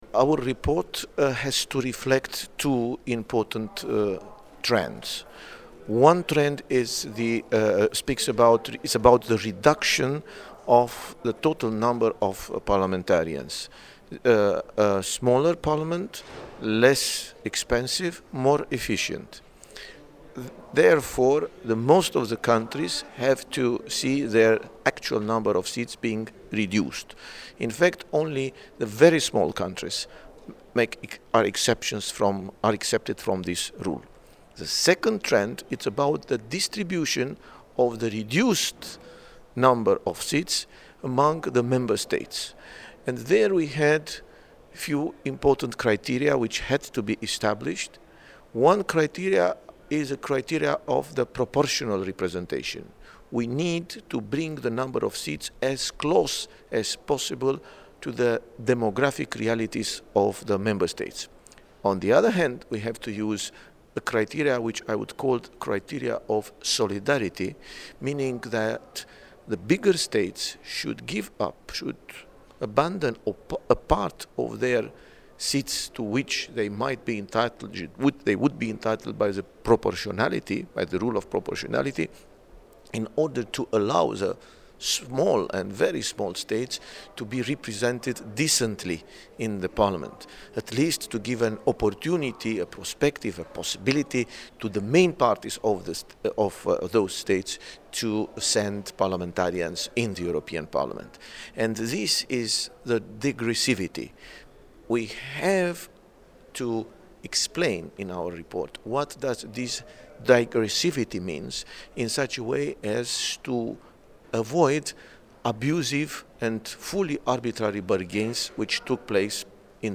Adrian Severin: «New Composition of the EP – Audio Statement» [mp3=4MB (4 min.):